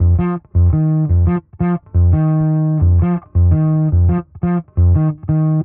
Index of /musicradar/dusty-funk-samples/Bass/85bpm
DF_PegBass_85-F.wav